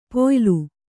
♪ poylu